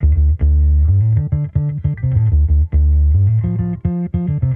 Index of /musicradar/sampled-funk-soul-samples/105bpm/Bass
SSF_PBassProc2_105D.wav